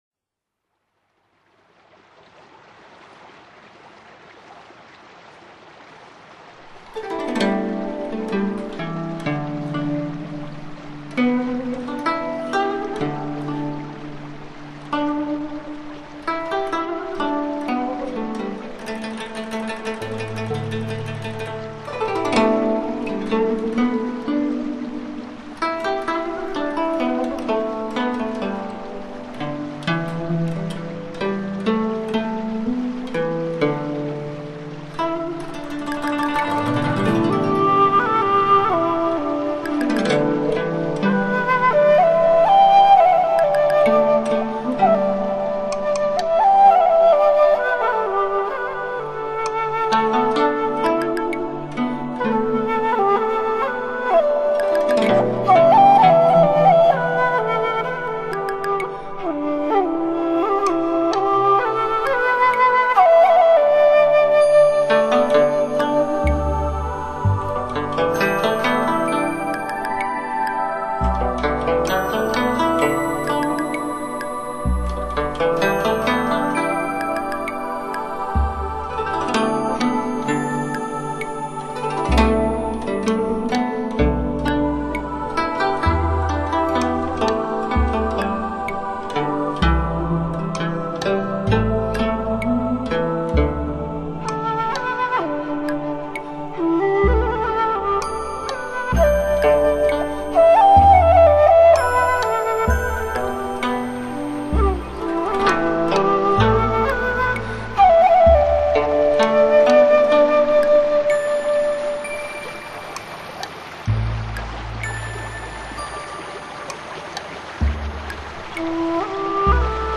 以江南音乐为底色，调弄出氤氲著山水灵气的新世纪旋律